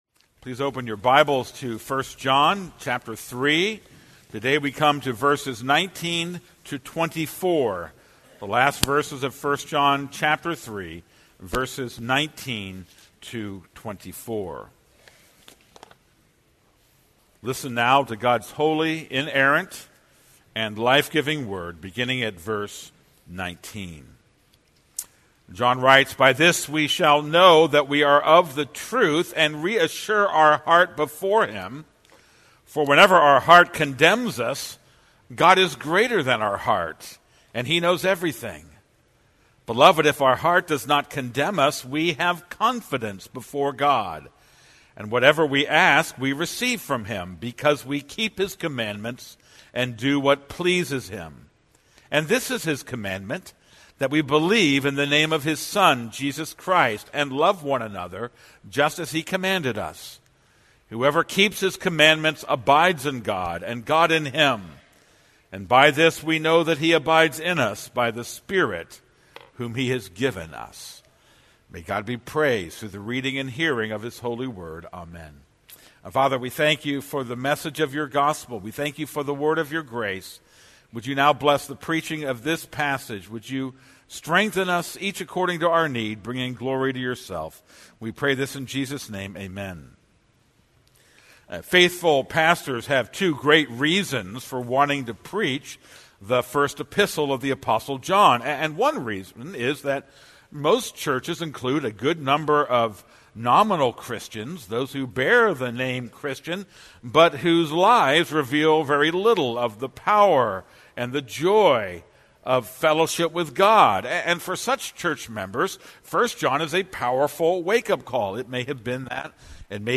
This is a sermon on 1 John 3:19-24.